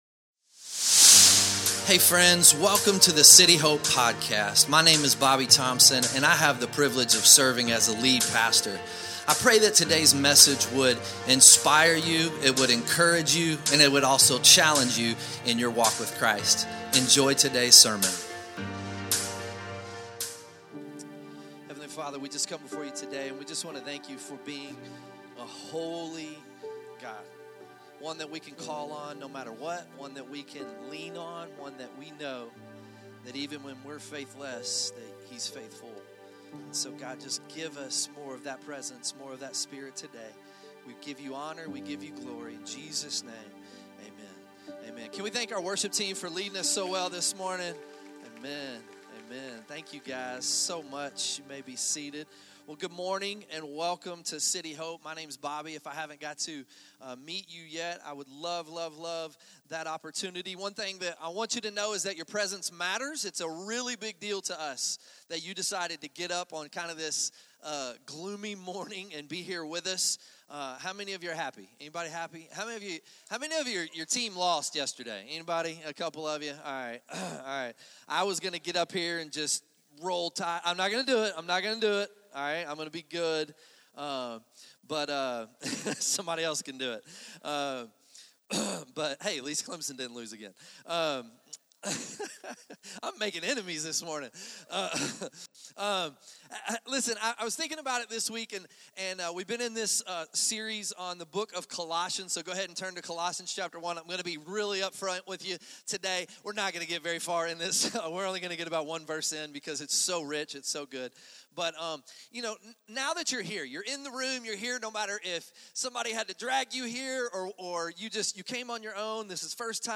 2025 Sunday Morning Unless we put Jesus first in everything